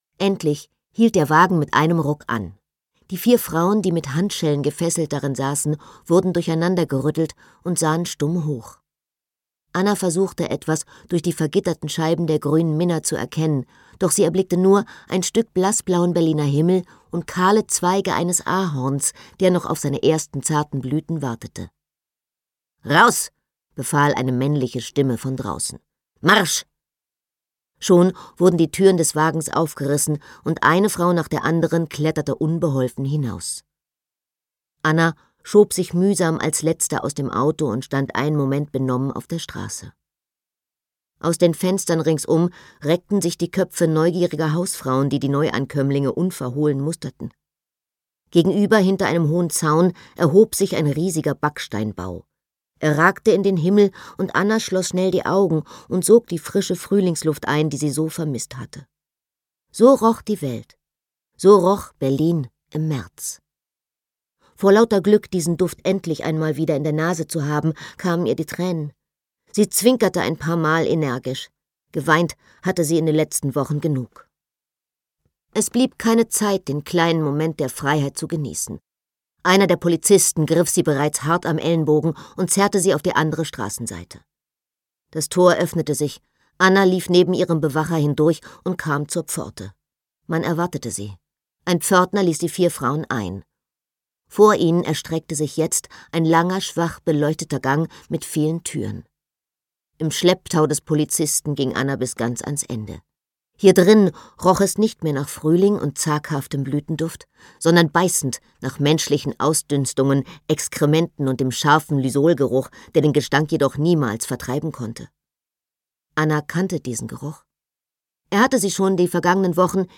Gekürzt Autorisierte, d.h. von Autor:innen und / oder Verlagen freigegebene, bearbeitete Fassung.
Fräulein Gold: Der Preis der Freiheit Gelesen von: Anna Thalbach
Anna ThalbachSprecherin